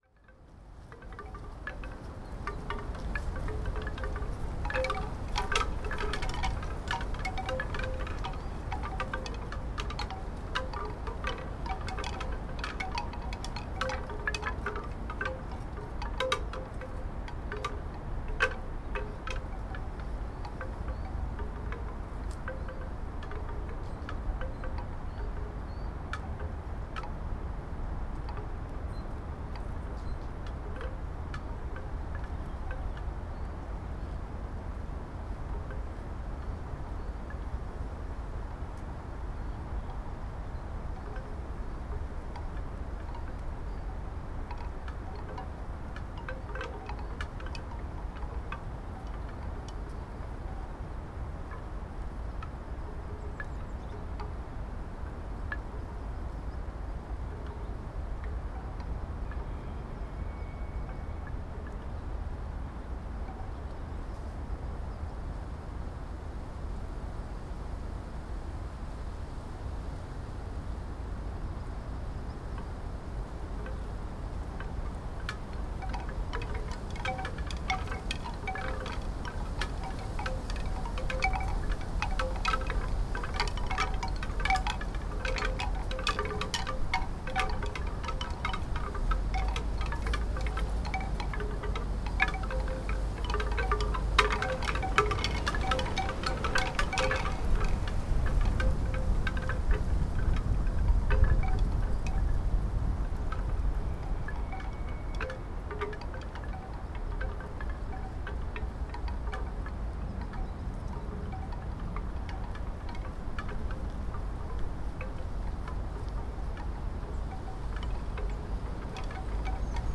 Wind Chimes, Bamboo - Hunter's Tor, Teign Gorge - excerpt
bamboo chimes Devon England February field-recording Hunters-Tor natural-soundscape sound effect free sound royalty free Nature